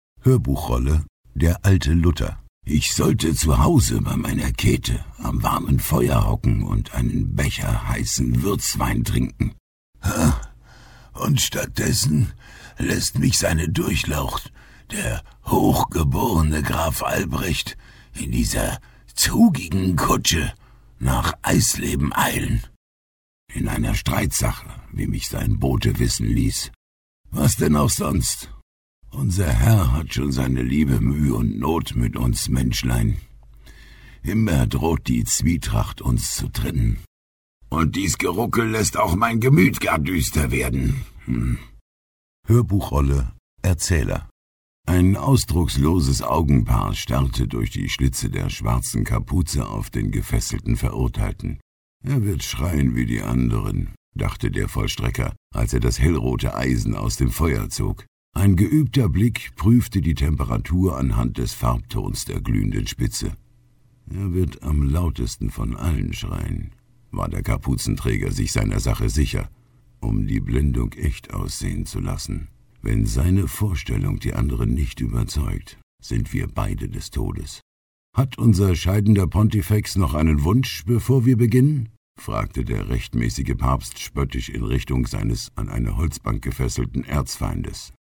Profisprecher deutsch. Angenehme tiefe Stimme, weiche dunkle Stimme, Seniorstimme, young senior
Sprechprobe: Sonstiges (Muttersprache):